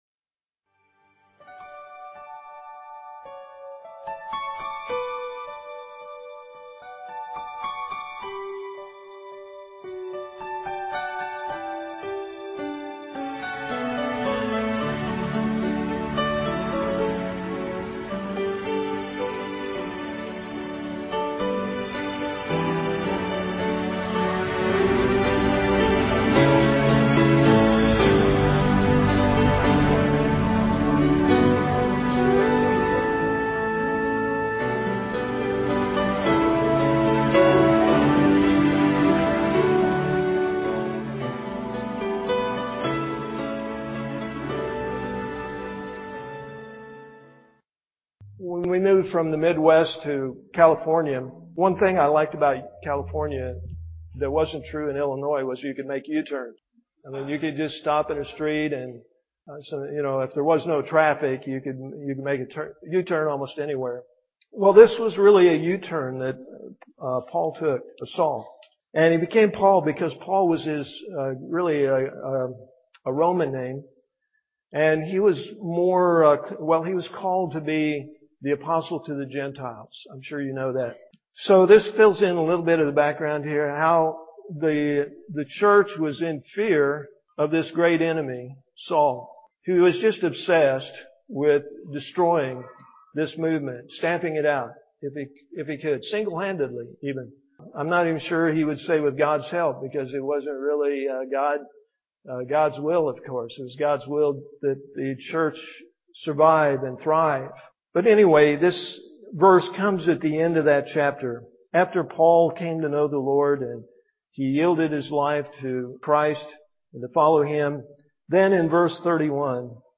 PLAY: The Ideal Church, September 19, 2010 Scripture: Acts 9:31. Message given
at Ewa Beach Baptist Church. Musical Intro/Outro: "How Beautiful."